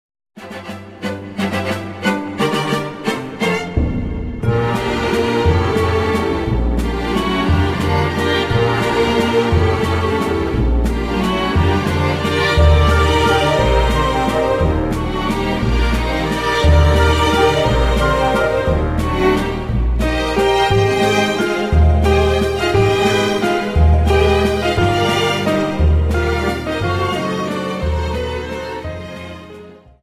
Viennese Waltz 59 Song